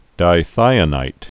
(dī-thīə-nīt)